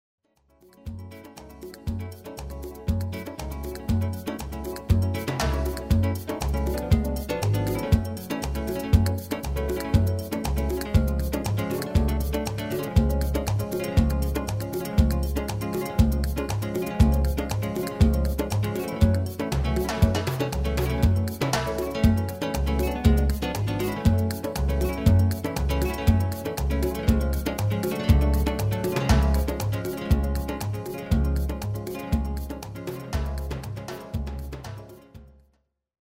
Genre: Reggae / Latin / Salsa
- Géén vocal harmony tracks
Demo's zijn eigen opnames van onze digitale arrangementen.